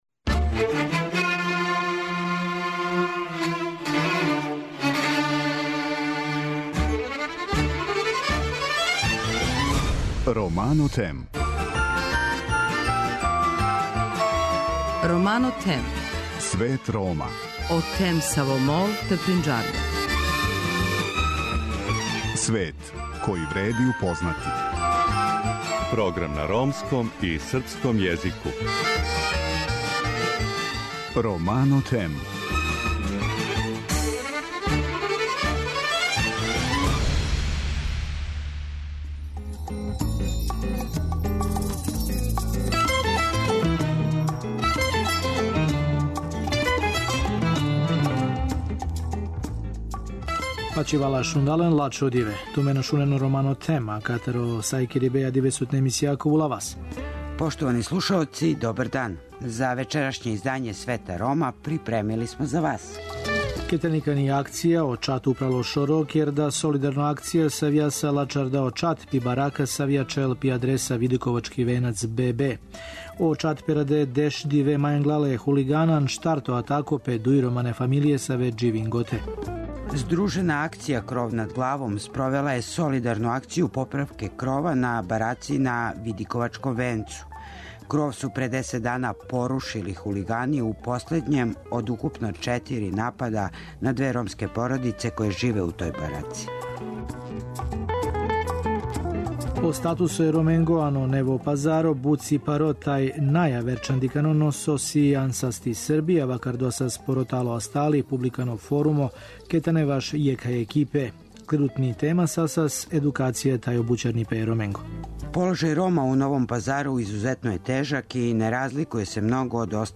Чућете репортажу о логору на Пагу који је био део комплекса смрти Госпић-Јадовно-Паг, у НДХ у којем је мучки убијено око 40 хиљада логораша.